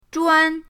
zhuan1.mp3